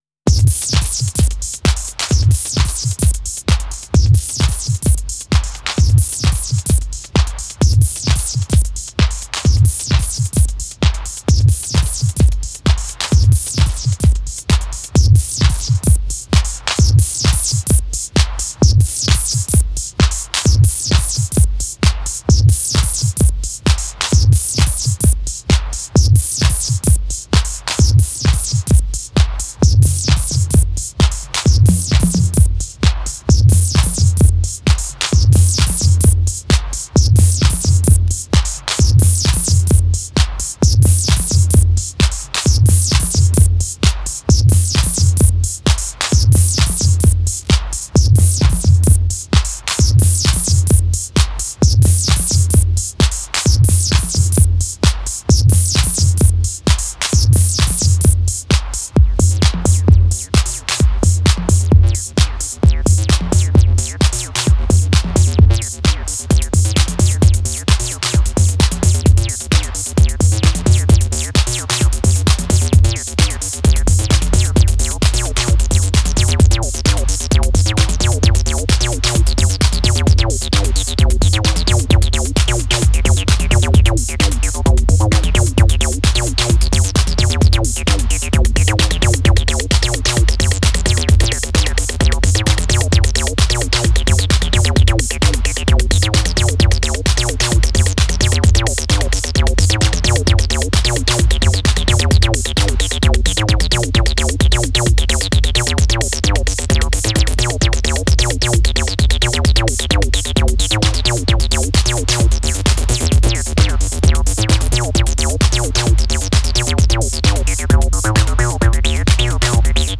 Genre: Electronic, Acid House, Deep House